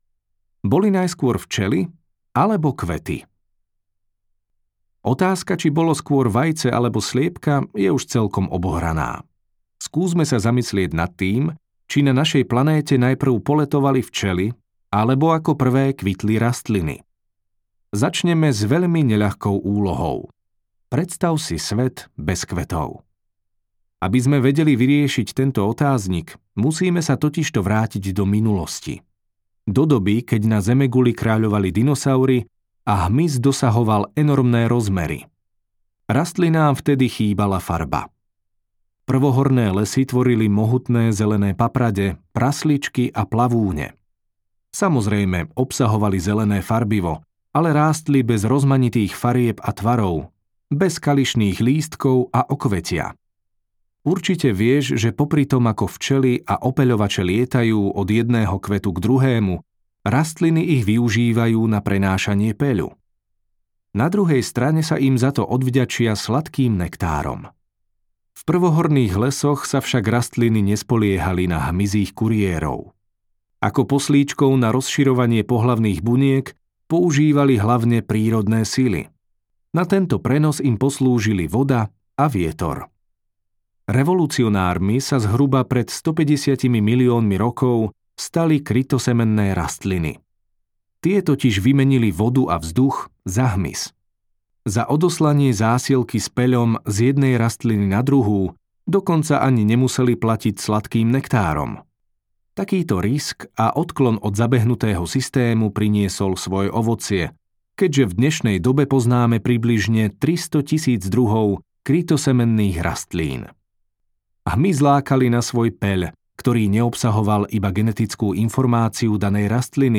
Audiokniha Vedecké okienko